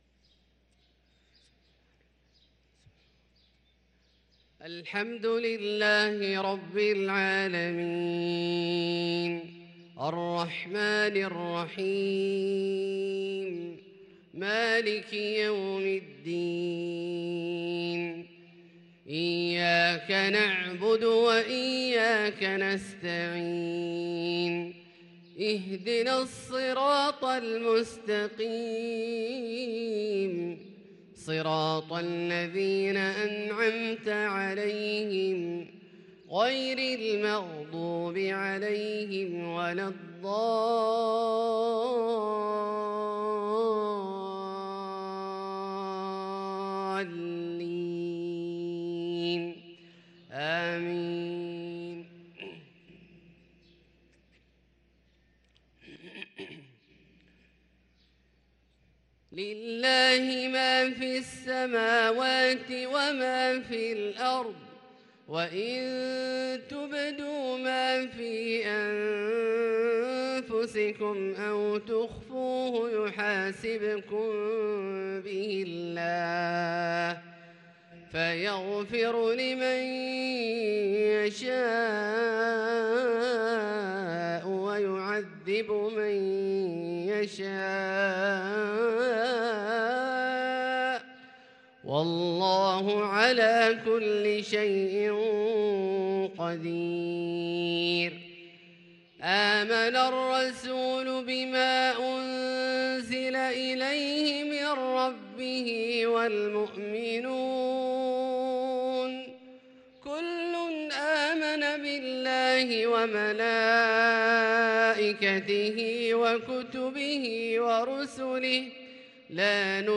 صلاة العشاء للقارئ عبدالله الجهني 10 جمادي الآخر 1444 هـ
تِلَاوَات الْحَرَمَيْن .